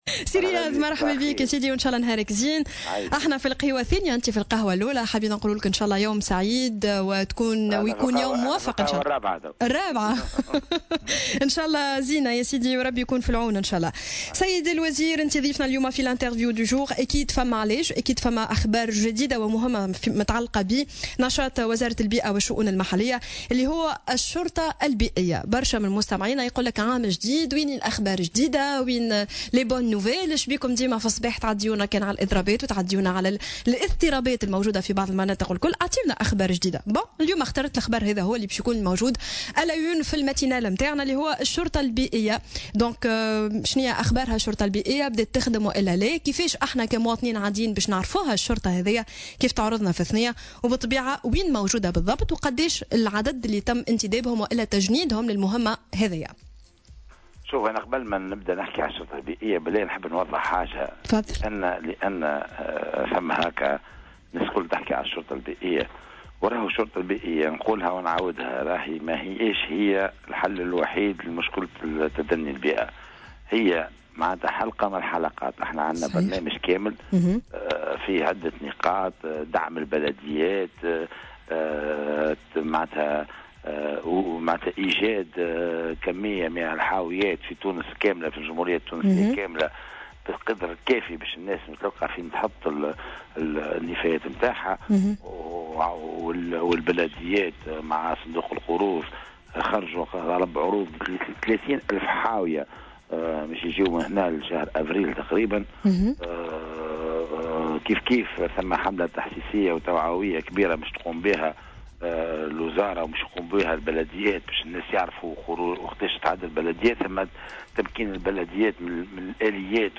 و أكدّ الوزير ضيف برنامج "صباح الورد" اليوم الخميس أنّ الشرطة البيئية إحدى حلقات النهوض بالبيئة في إطار برنامج متكامل للوزارة من بين أسسه دعم البلديات و توفير العدد اللازم من الحاويات، و أشار الوزير في هذا الصدد إلى تقديم طلب عروض سيتم بموجبه توفير 30 ألف حاوية خلال شهر أفريل 2017 .